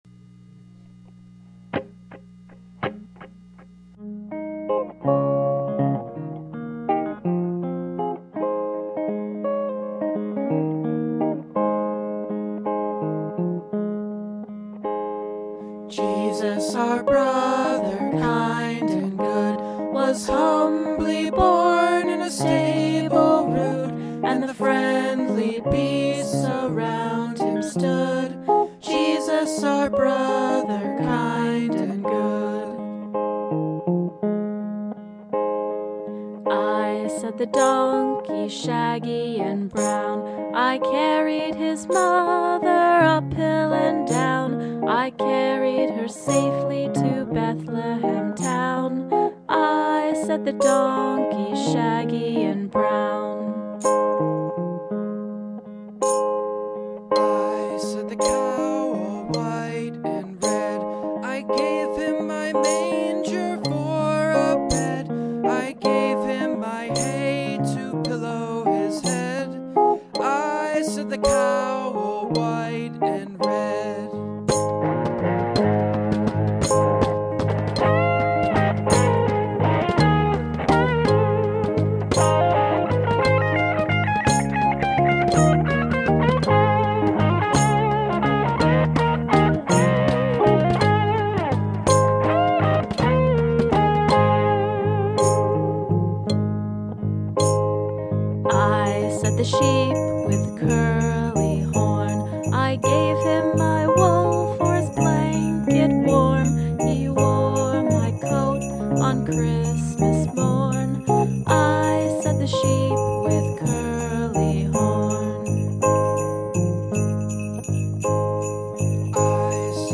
vocals, jingle bells, and hand claps
vocals, guitar, bass, cowbell, tambourine, jungle snare, and hand claps
Words: French carol, 12th century
Music: ORIENTIS PARTIBUS, French melody, early 13th century